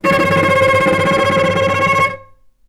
healing-soundscapes/Sound Banks/HSS_OP_Pack/Strings/cello/tremolo/vc_trm-C#5-mf.aif at ae2f2fe41e2fc4dd57af0702df0fa403f34382e7
vc_trm-C#5-mf.aif